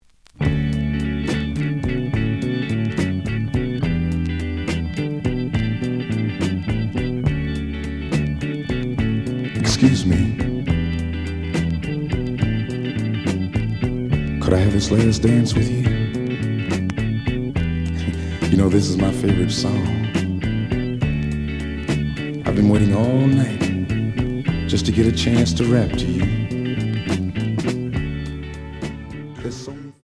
The audio sample is recorded from the actual item.
●Genre: Soul, 70's Soul
Looks good, but slight noise on A side.)